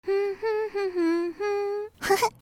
GameHappyAudio.mp3